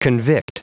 Prononciation du mot convict en anglais (fichier audio)
Prononciation du mot : convict